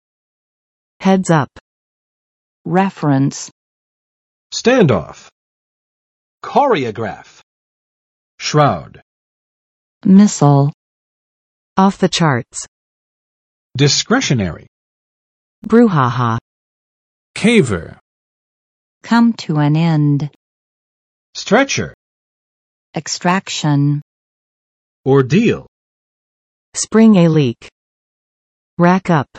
[ˋhɛdzˋʌp] n. 小心，注意（用于提醒某事要发生）
heads-up.mp3